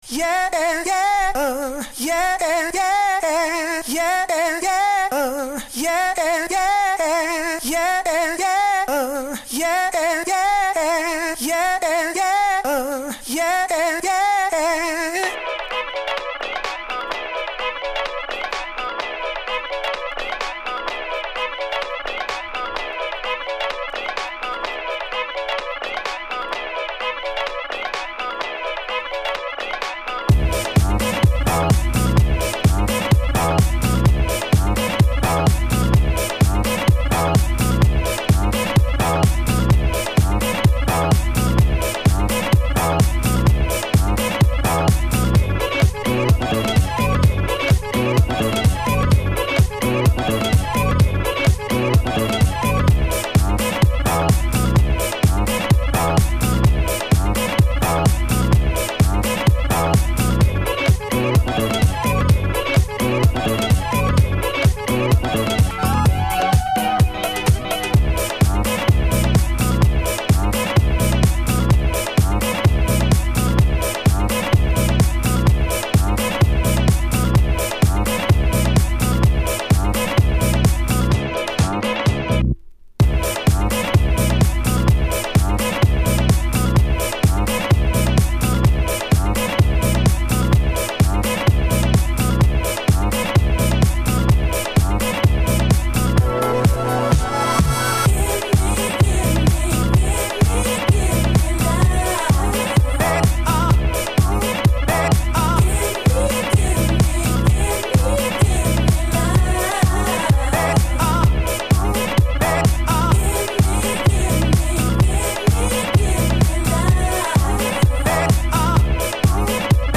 RSCR:Respond: Free House Music!